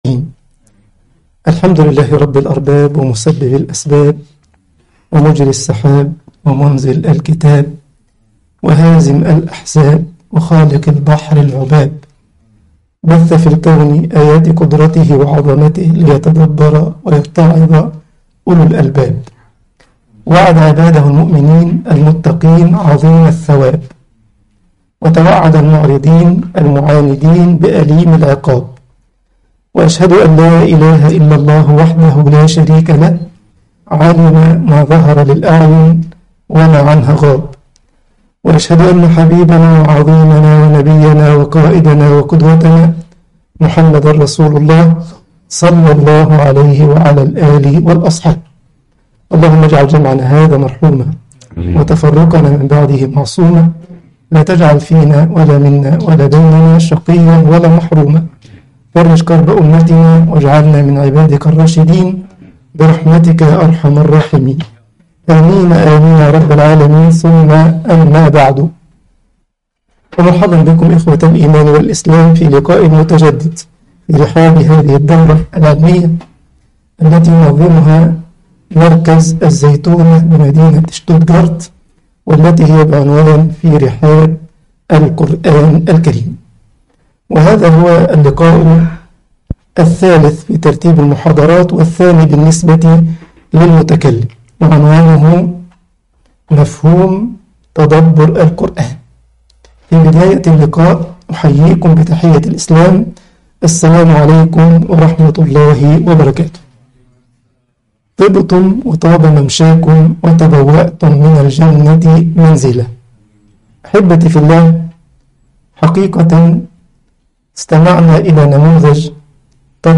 مركز الزيتونة المحاضرة 2 مفهوم التدبر و ضوابطه